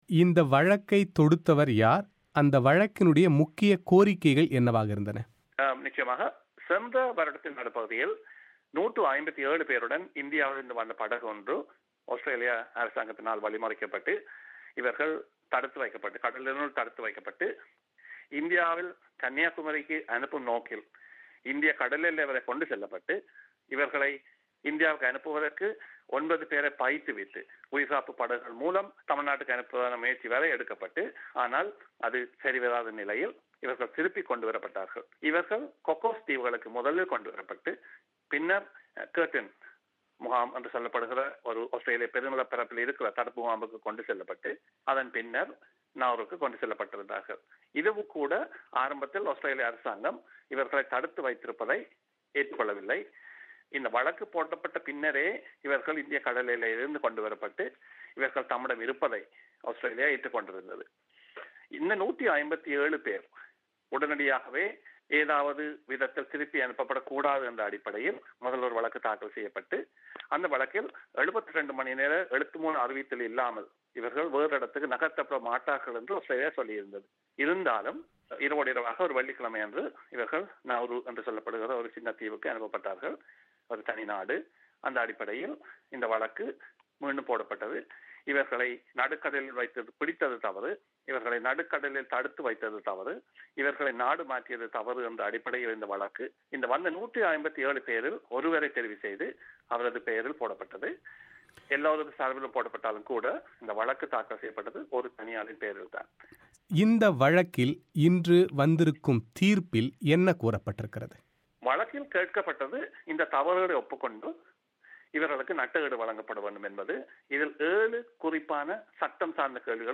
பிபிசி தமிழோசைக்கு அளித்த பிரத்யேக செவ்வி